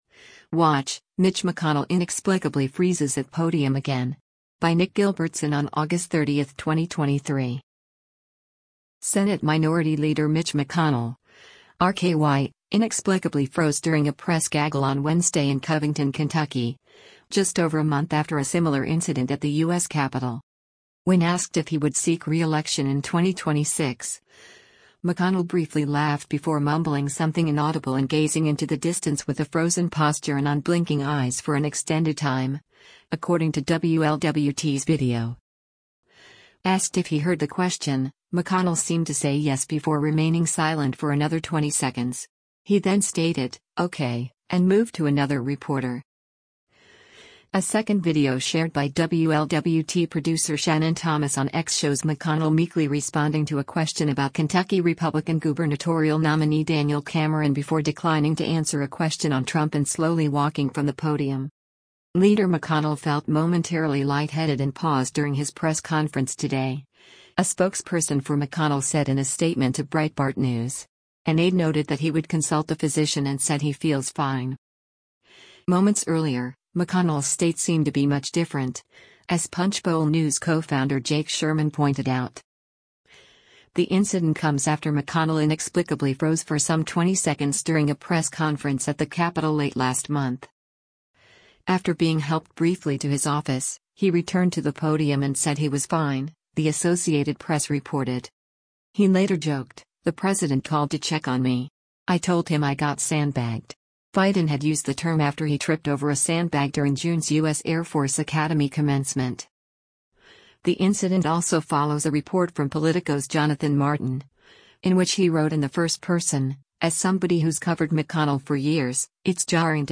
Senate Minority leader Mitch McConnell (R-KY) inexplicably froze during a press gaggle on Wednesday in Covington, Kentucky, just over a month after a similar incident at the U.S. Capitol.
When asked if he would seek reelection in 2026, McConnell briefly laughed before mumbling something inaudible and gazing into the distance with a frozen posture and unblinking eyes for an extended time, according to WLWT’s video.
Asked if he heard the question, McConnell seemed to say “Yes” before remaining silent for another 20 seconds.